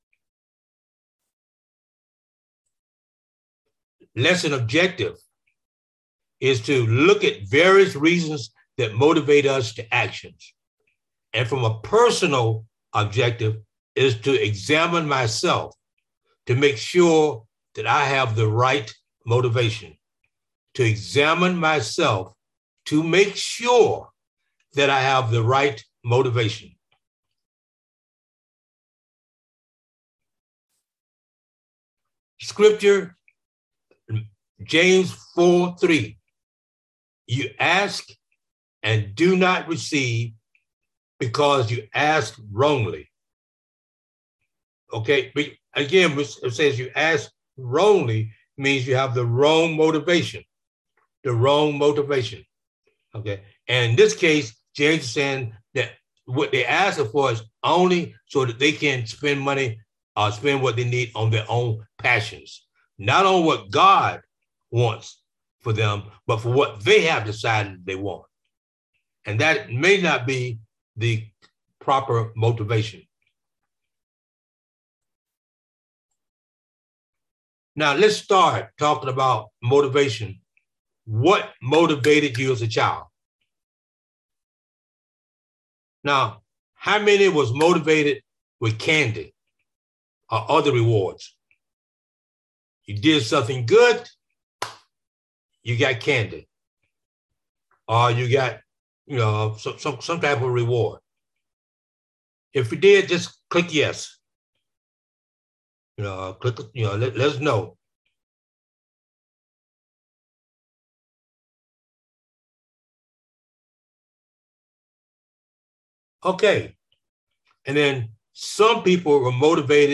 From Series: "Sunday Service"